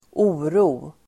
Uttal: [²'o:ro:]